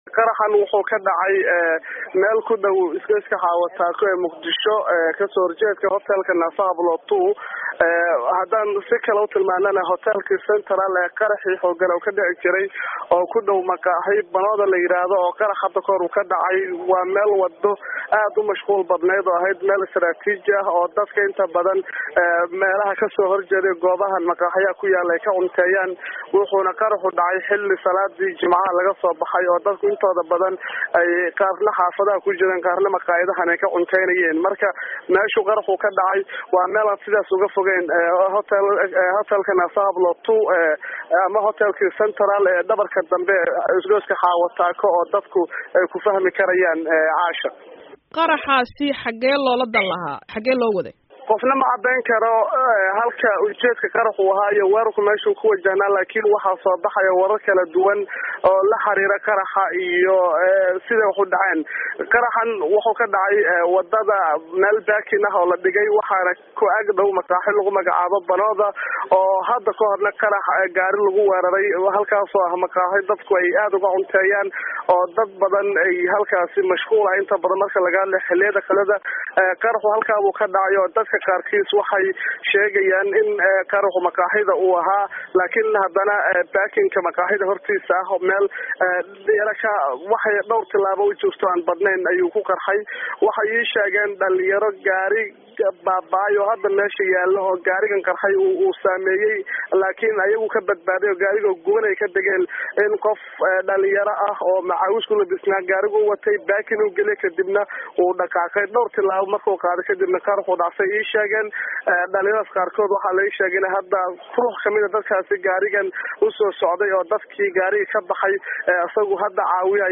waxaanan ugu horreyntii weydiiyay inuu sawir ka bixiyo goobta uu qaraxu ka dhacay.